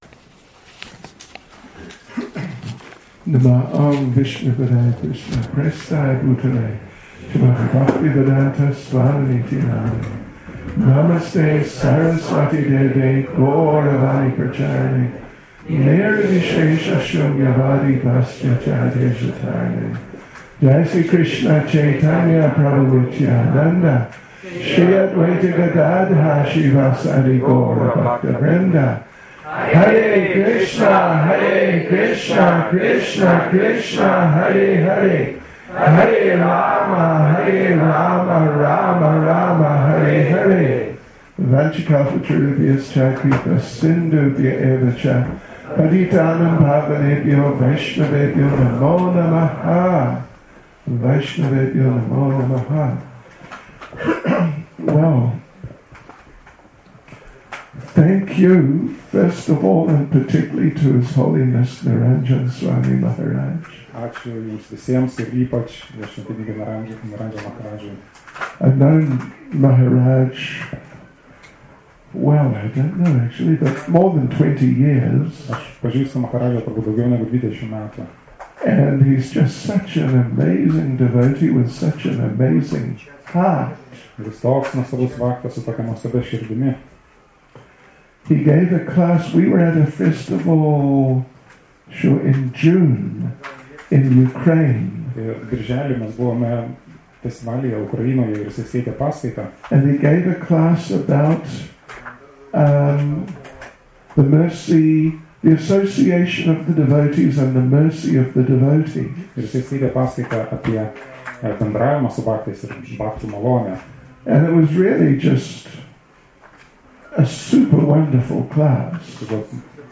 Our Deep Connections Vyasa Puja Address, Vaisnava Summer Festival, Lithuania